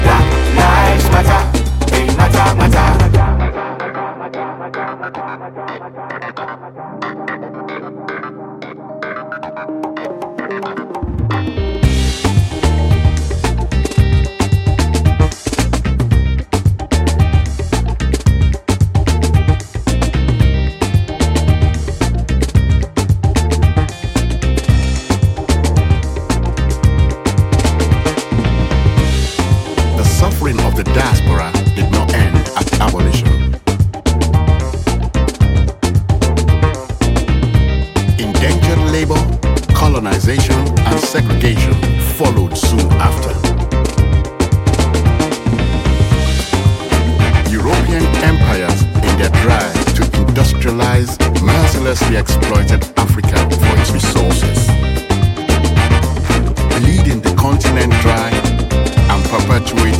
ジャンル(スタイル) SOULFUL HOUSE / AFRO HOUSE